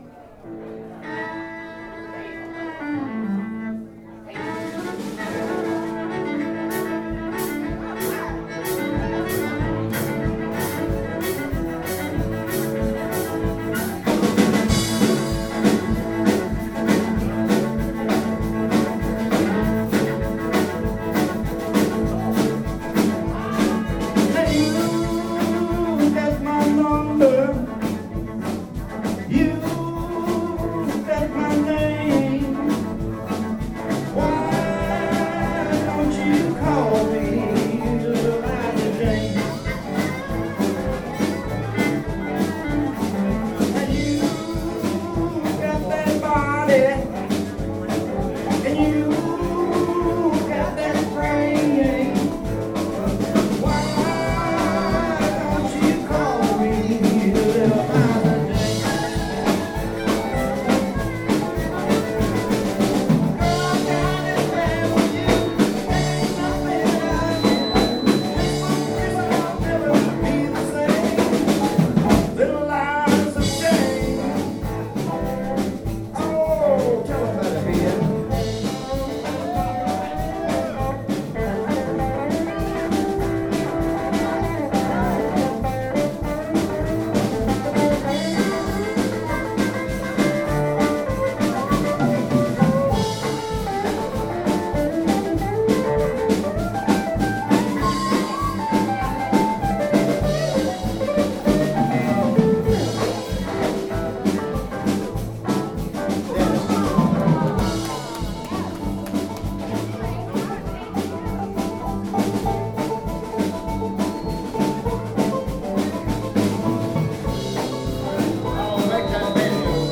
Magnolia South at the America Legion Hall at Lake Ella, Tallahassee, Florida